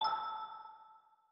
BigNotifySound.mp3